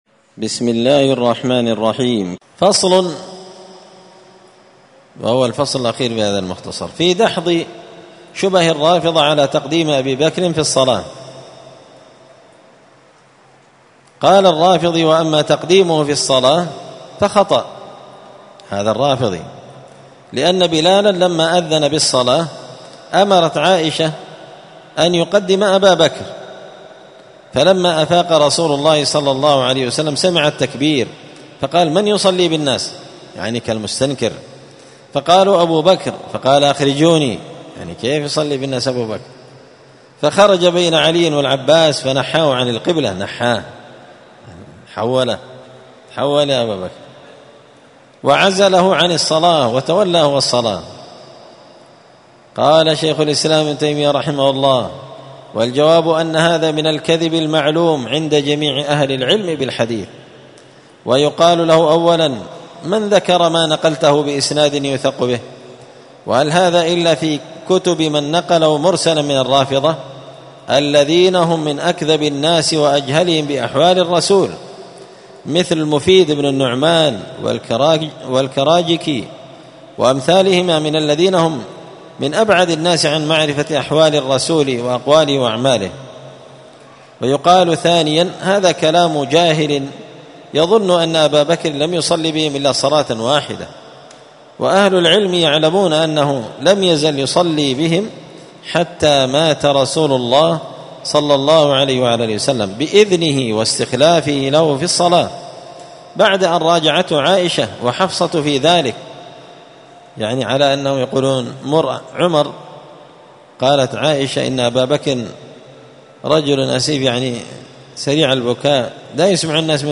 الأربعاء 19 ربيع الأول 1445 هــــ | الدروس، دروس الردود، مختصر منهاج السنة النبوية لشيخ الإسلام ابن تيمية | شارك بتعليقك | 60 المشاهدات
مسجد الفرقان قشن_المهرة_اليمن